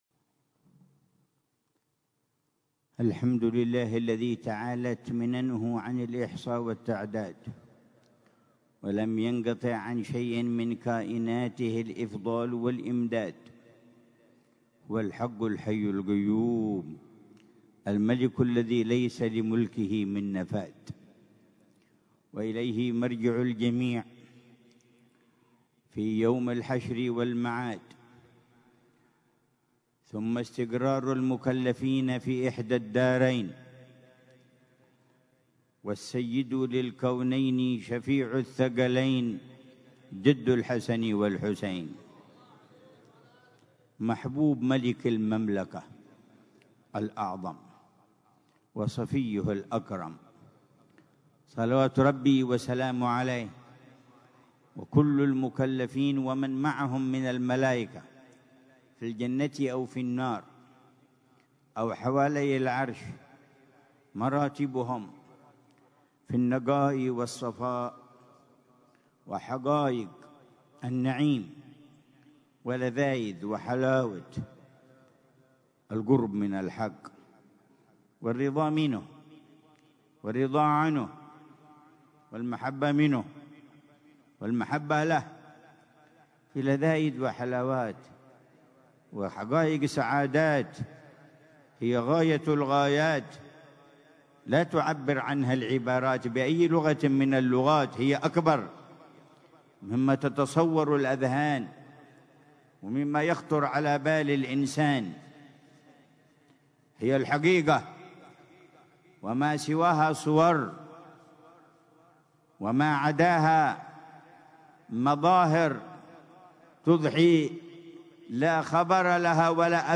محاضرة العلامة الحبيب عمر بن محمد بن حفيظ ضمن سلسلة إرشادات السلوك ليلة الجمعة 7 جمادى الثانية 1447هـ في دار المصطفى، بعنوان: